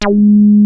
WEST SOFT#G3.wav